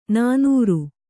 ♪ nānūru